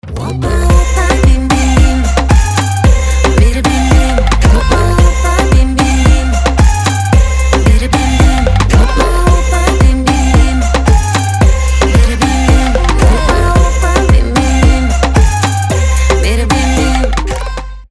веселые
заводные
женский голос
Trap
Moombahton